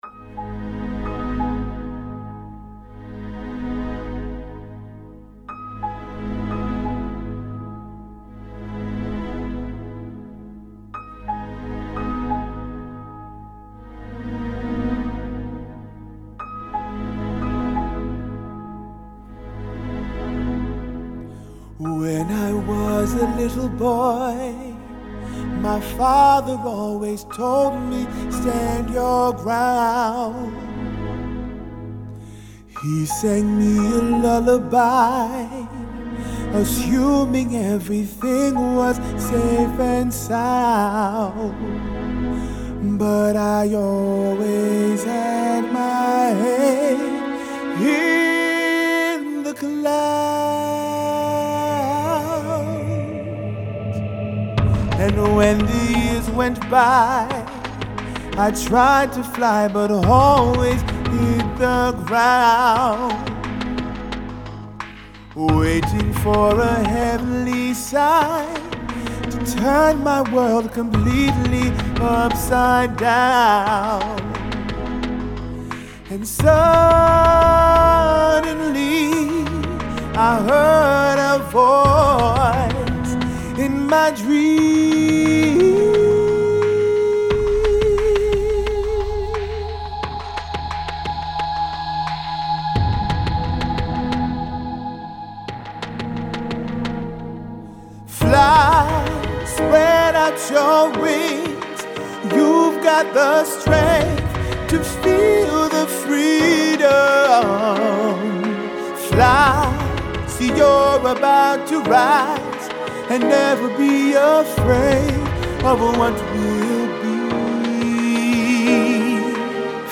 Musik für Artisten
GOTHIC OPERA